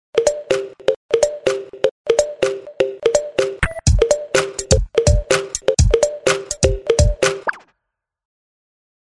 알림음 Kettle
kettle.mp3